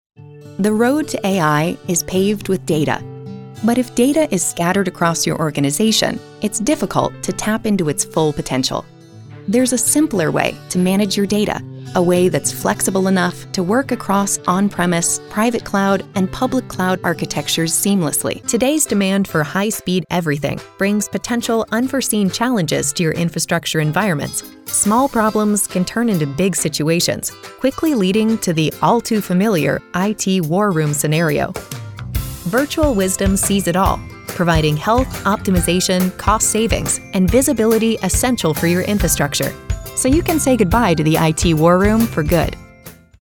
Female Voice Over Talent
Friendly, Warm, Conversational.
Corporate